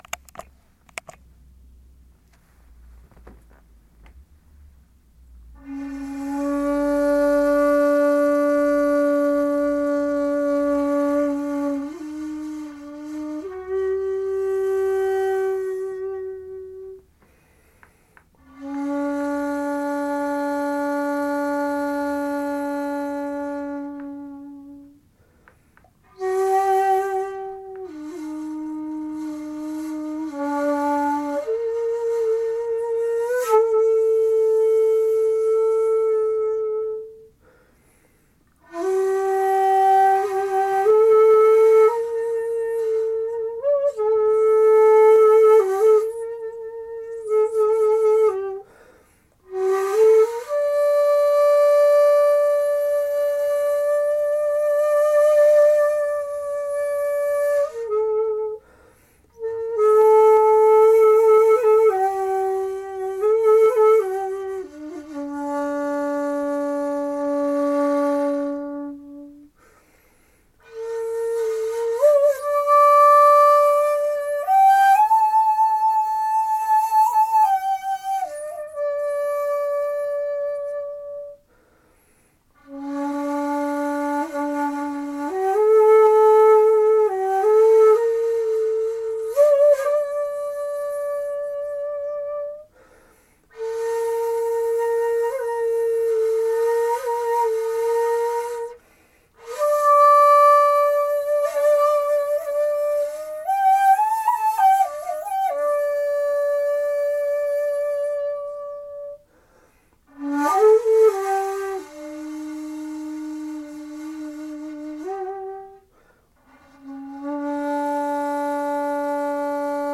以上のことを見てきますと古典本曲の『手向』こそ、題名も哀調を帯びた音の流れも正に"供養"にふさわしい曲だと思います。
誰も居ない時でしたので墓前で「手向」を吹かせていただきました。
琴古流奏者の吹く「手向」で すが、お聴ききになりながら以下の報告をお読み下されば幸いです。
日暮れにはまだ時間がありましたし回りに誰も居なかったので古墳の前で「手向」を吹かせていただき、再度手を合わせてから當麻寺へと急ぎました。